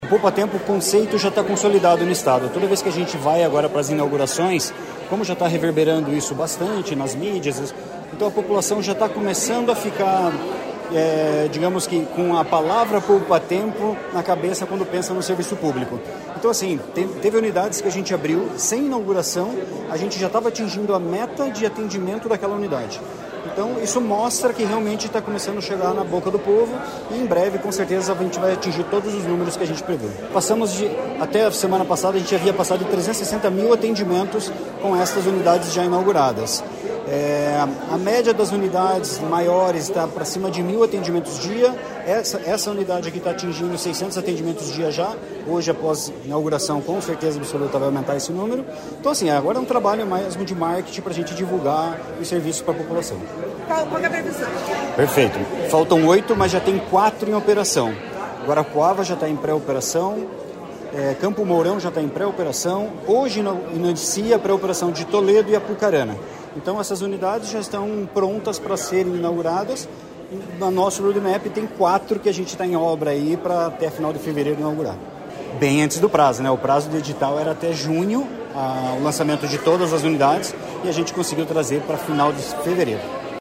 Sonora do superintendente estadual de Governança e Dados, Leandro Moura, sobre a inauguração da unidade do Poupatempo no bairro Boa Vista, em Curitiba